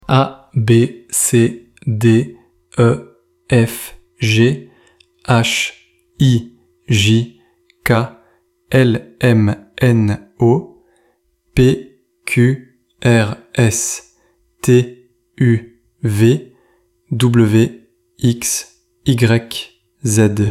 ElevenLabs_Text_to_Speech_audio.mp3